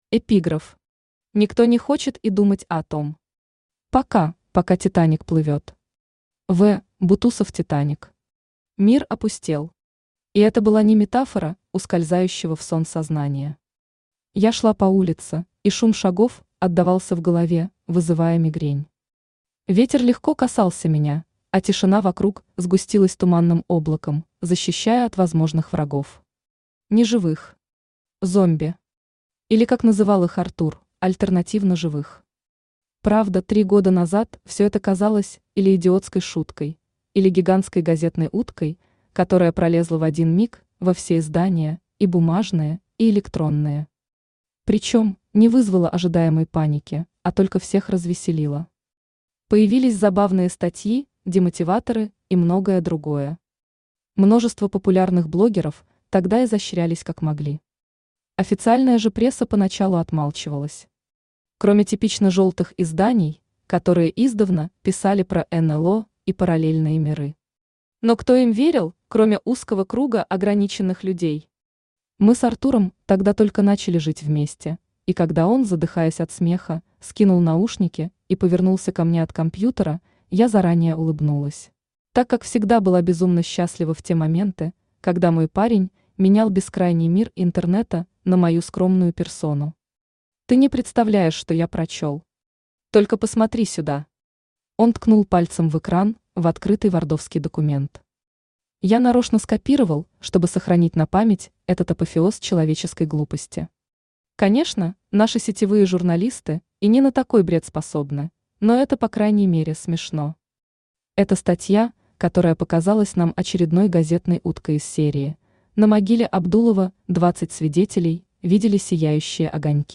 Аудиокнига Наш пустой мир | Библиотека аудиокниг
Aудиокнига Наш пустой мир Автор Кристина Воронова Читает аудиокнигу Авточтец ЛитРес.